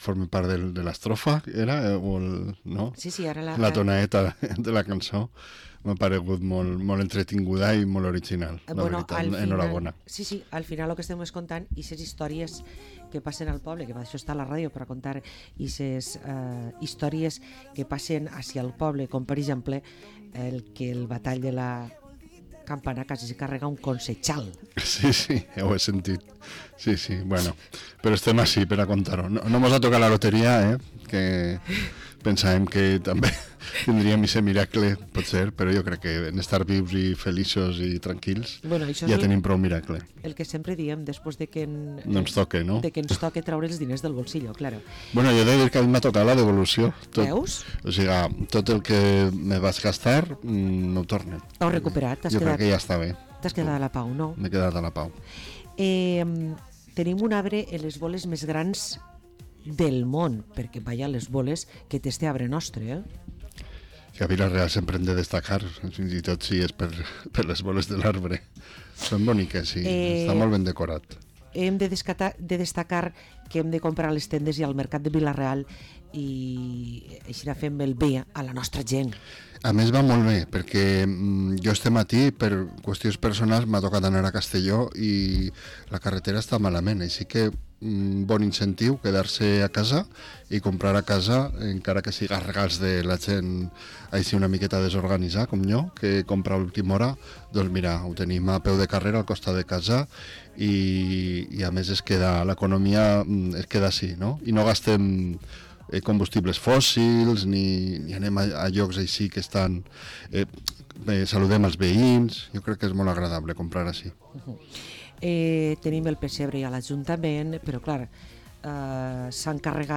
Parlem amb Santi Cortells, portaveu i regidor de Compromís per Vila-real